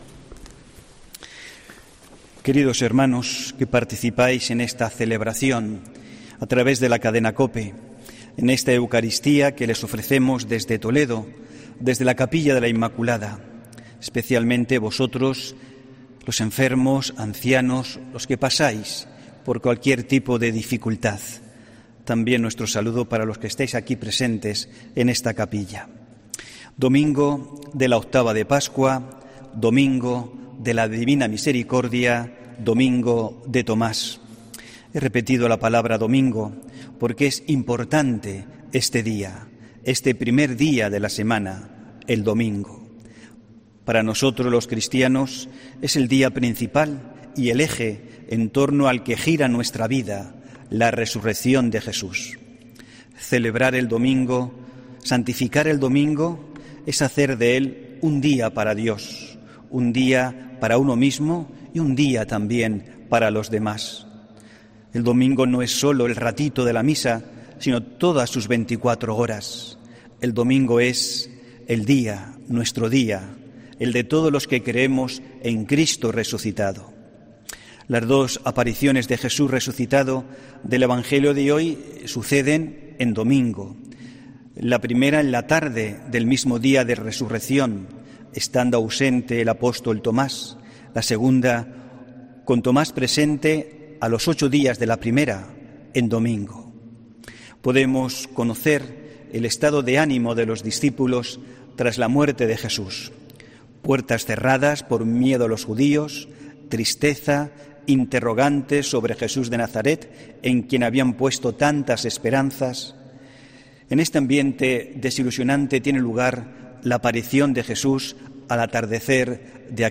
HOMILÍA 11 ABRIL 2021